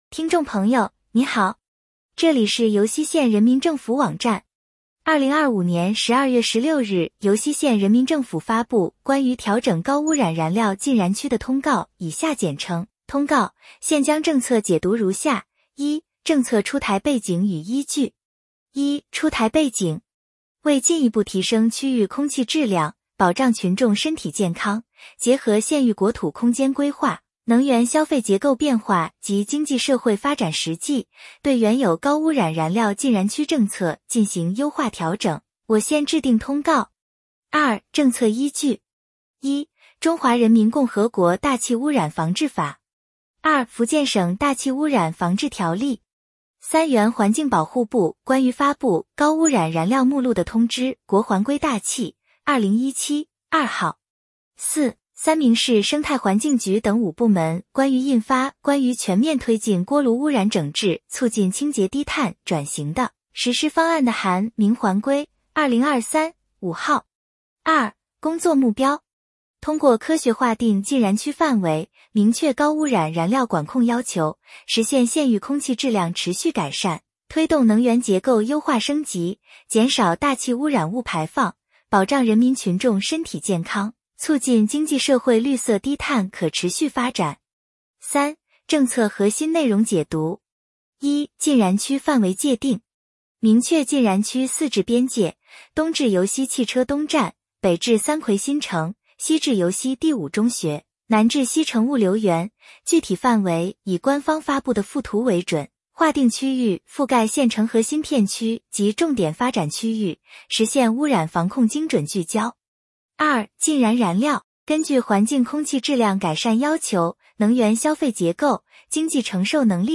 音频解读：《尤溪县人民政府关于调整高污染燃料禁燃区的通告》